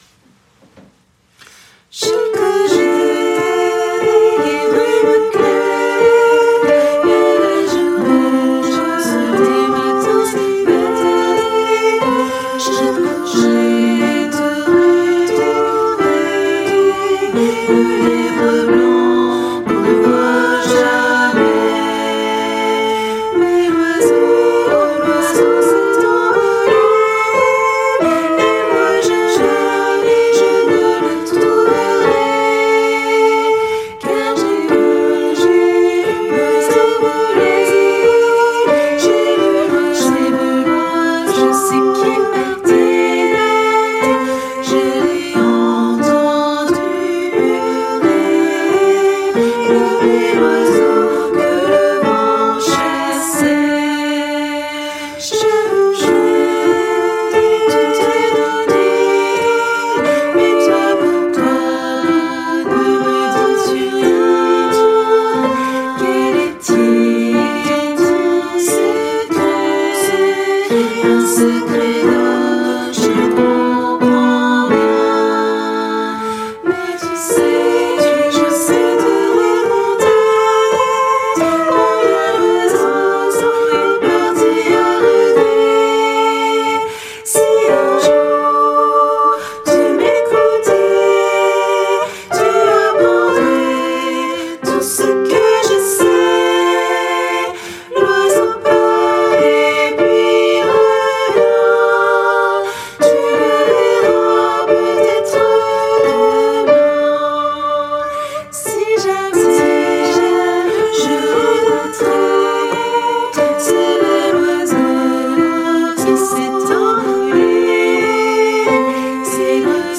- Oeuvre pour choeur à 4 voix mixtes (SATB)
Tutti